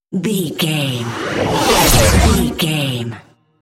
Whoosh to hit engine speed
Sound Effects
Atonal
dark
futuristic
intense
tension
the trailer effect